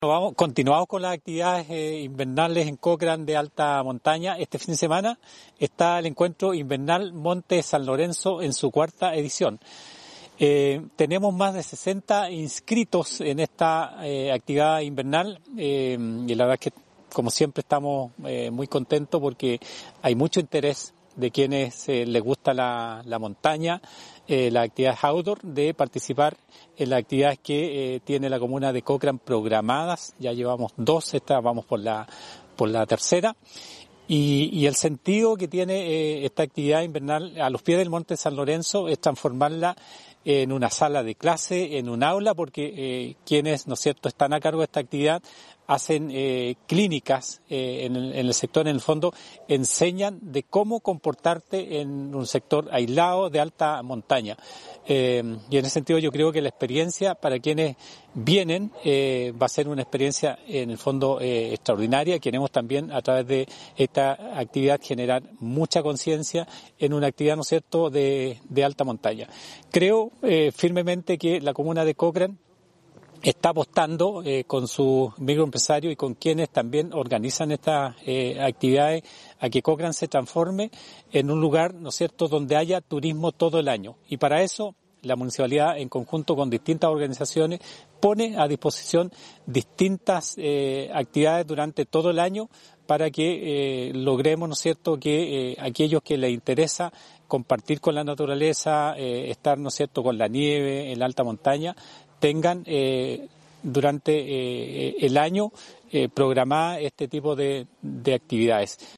PATRICIO ULLOA GEORGIA – ALCALDE DE COCHRANE